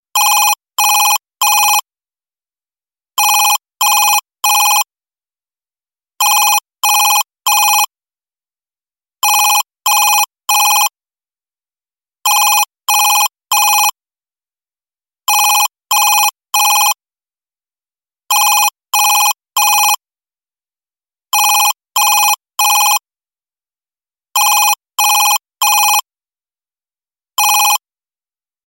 zvonok-ofisnogo-telefona_24626.mp3